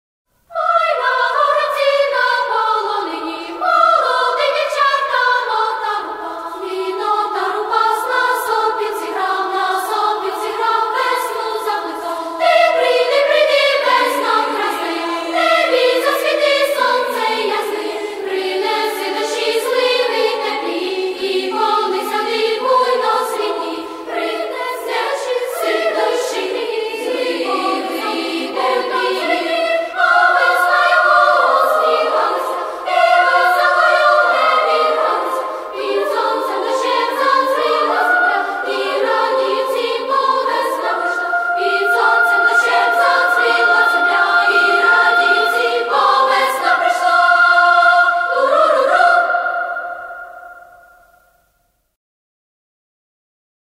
Веснянка. (Українська народна пісня, обробка О.Яковчука)
vesnjanka_.mp3